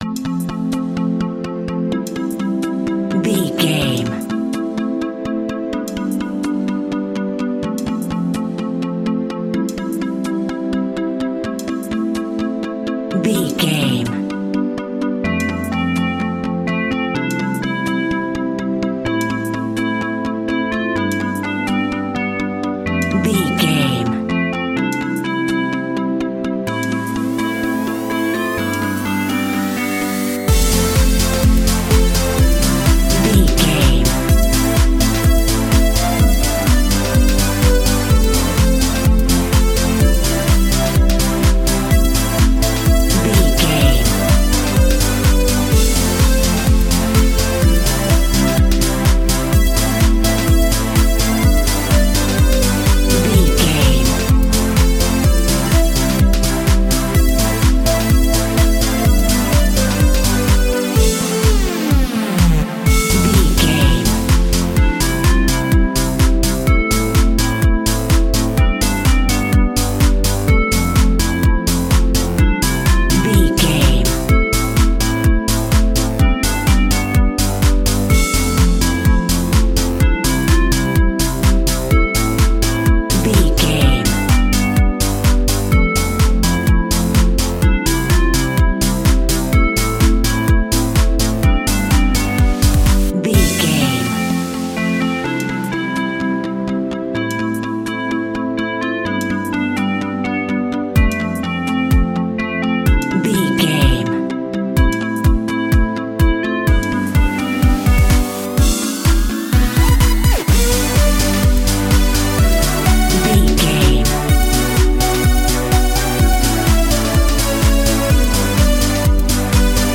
Clubbing Electronica.
Ionian/Major
uplifting
futuristic
hypnotic
dreamy
groovy
drum machine
synthesiser
techno
trance
synth leads
synth bass